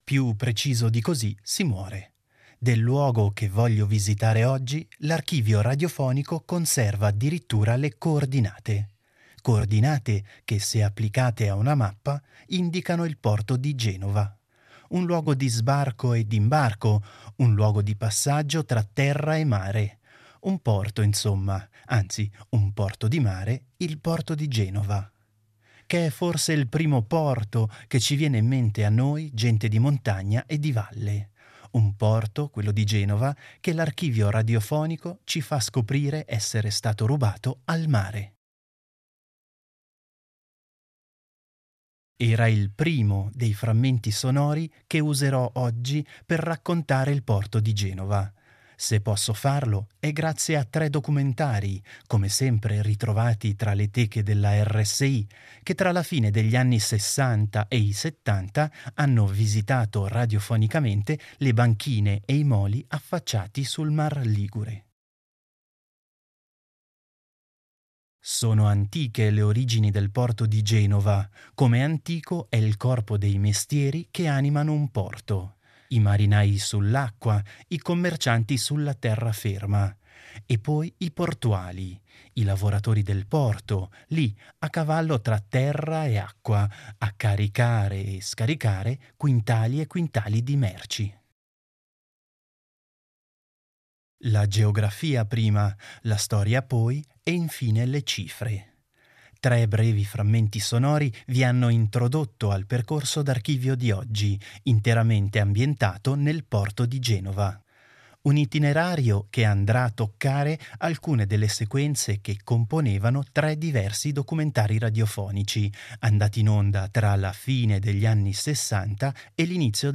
“Granaio della memoria” propone all’ascolto una visita sonora al porto Genova.
Saranno allora tre i documentari, registrati a cavallo tra gli anni Sessanta e i Settanta, dai quali il “Granaio” ricaverà i frammenti sonori che andranno a comporre il suo abituale percorso d’archivio.